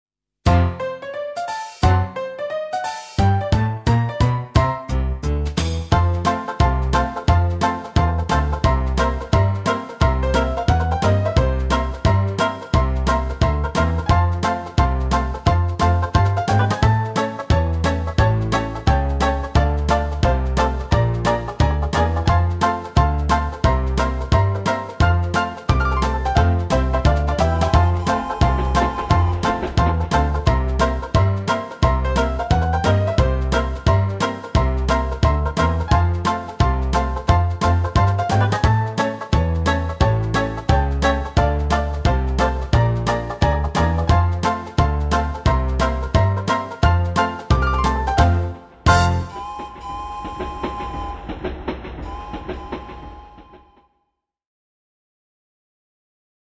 Meespeel CD